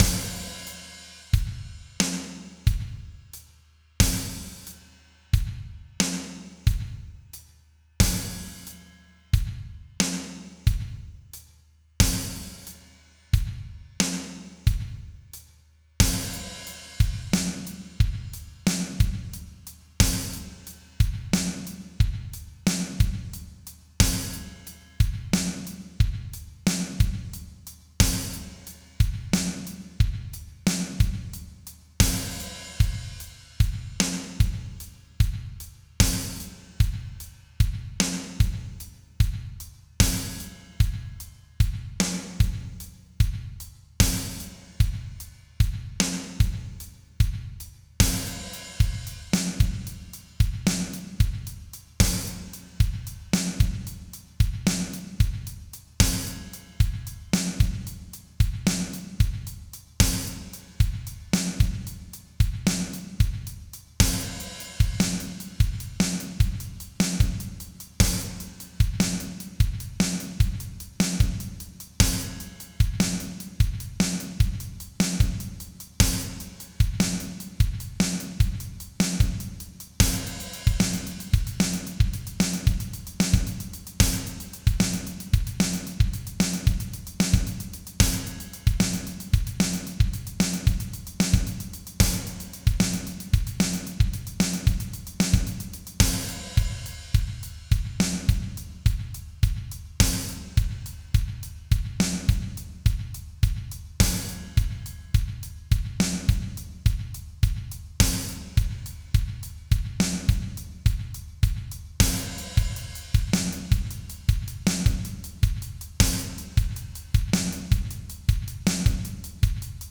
Key to Polyrhythm Demonstration
Start End Kick Snare Hi-Hat Floor Tom Medium Tom High Tom
In all except the last demonstration, the kick drum and snare represent a particular polyrhythm (e.g., 0:00-0:16 demonstrate a 3:2 polyrhythm), with the hi-hat serving as a metronome of sorts.
The final demonstration demonstrates a 7:6:5:4:3:2 polyrhythm.
polyrhythm_demonstration.flac